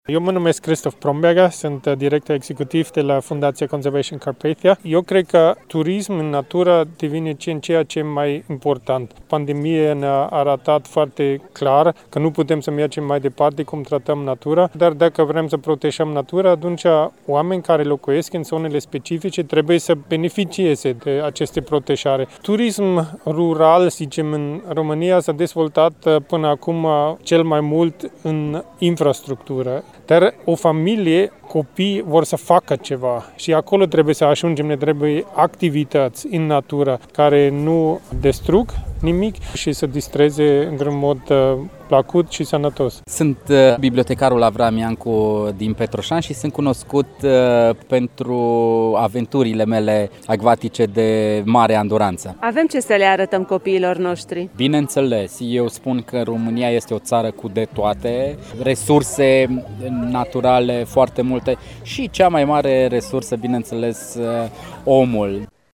Turismul rural, ecologic, montan poate reprezenta o soluție de vacanță în vremuri de pandemie, susțin promotorii turismului: